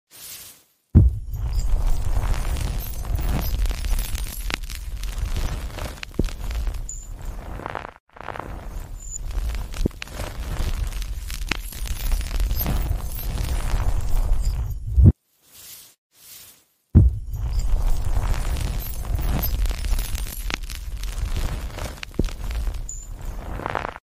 Watch an AI hand create a crystalline forest with a single touch! ✨ The sound of impossible growth.